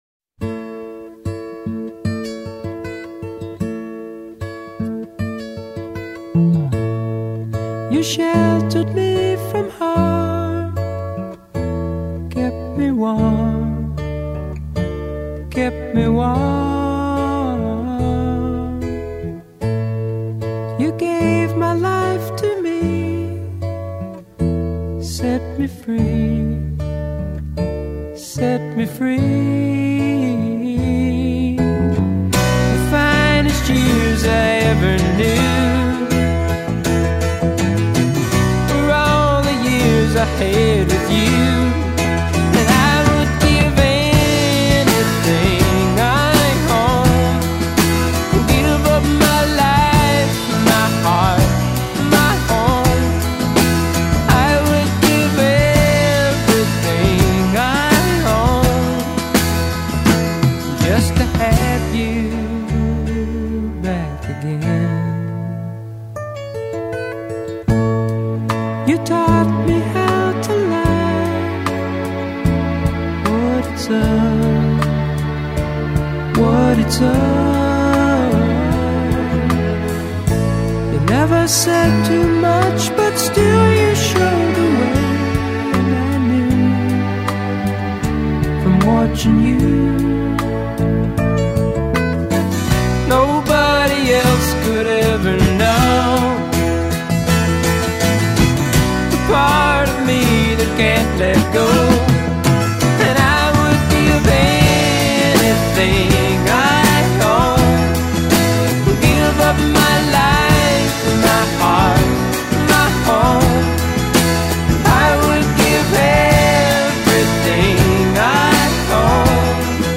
Some 1970’s syrup